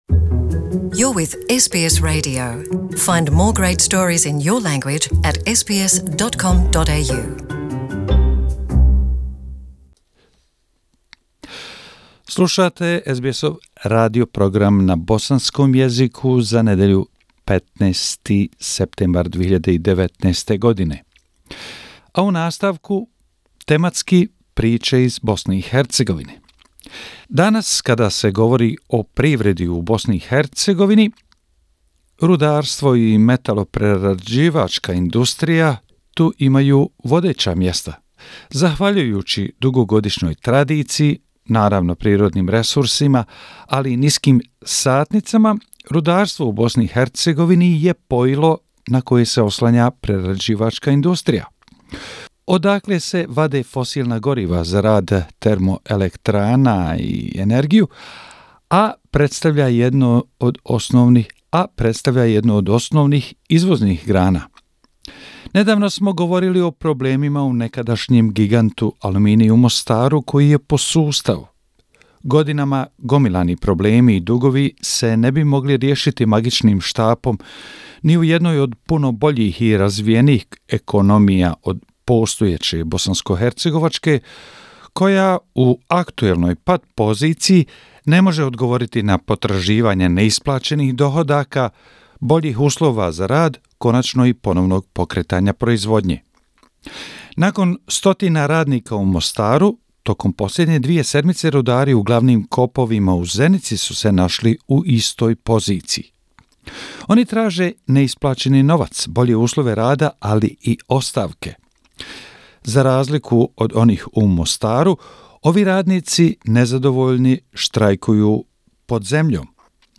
Bosnia and Herzegovina - affairs in the country for the last seven day, weekly report September 15, 2019